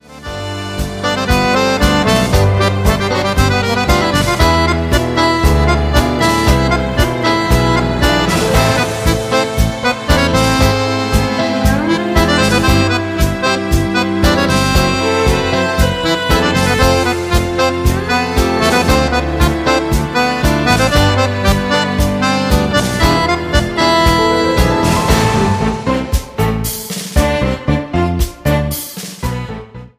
TANGO  (03,23)